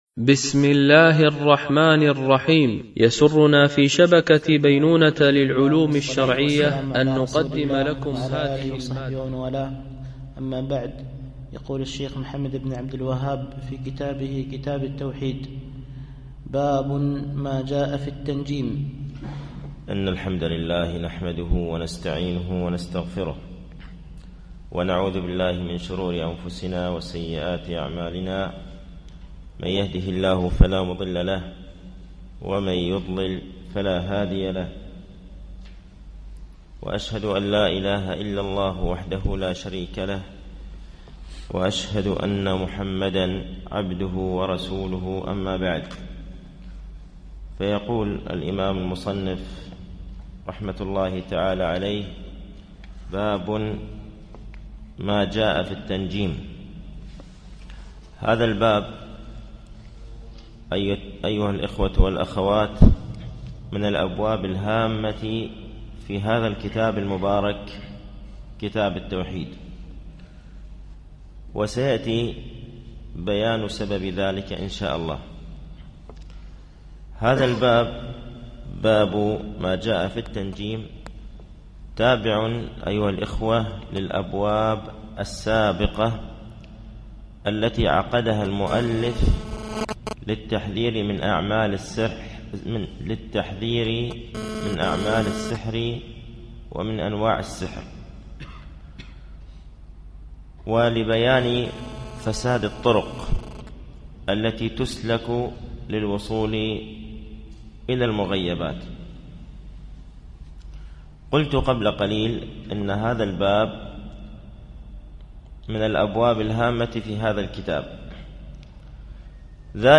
التعليق على القول المفيد على كتاب التوحيد ـ الدرس الرابع و التسعون